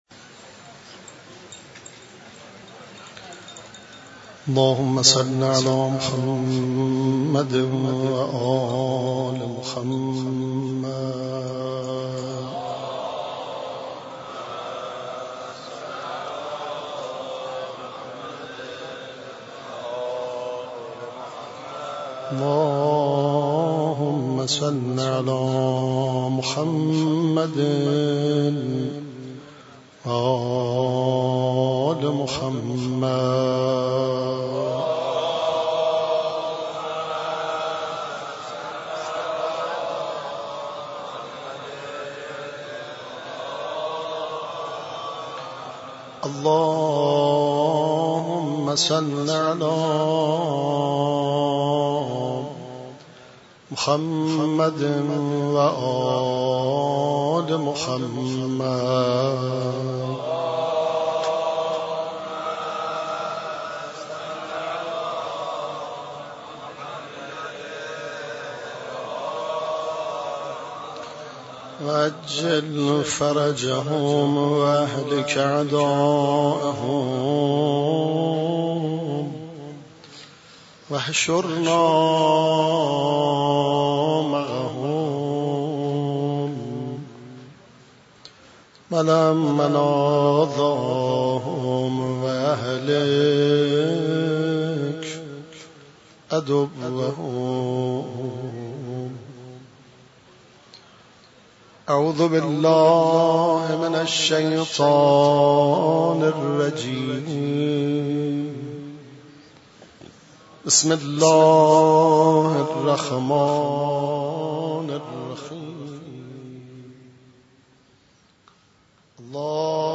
مراسم شب 19 , 21 ماه رمضان
در مسجد شهدا برگزار گردید
قرائت مناجات منظوم حضرت امیر (علیه السلام) ، روضه امام علی (علیه السلام)